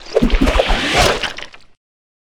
dong1.ogg